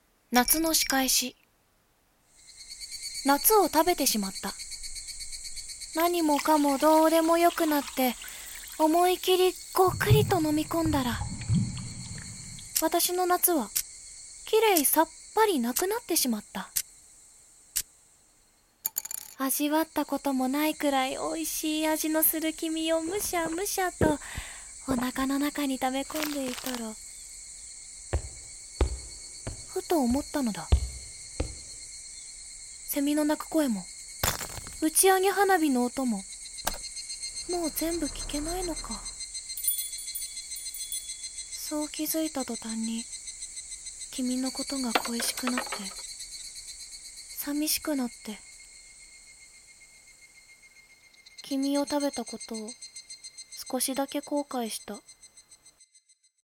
【朗読】夏の仕返し